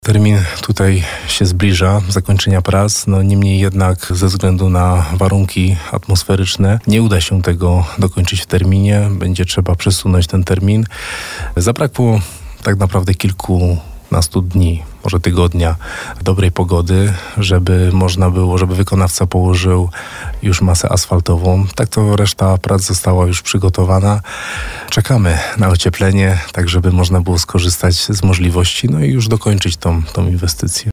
Jak powiedział nam wójt Jacek Kaliński, niestety termin ten nie zostanie dotrzymany.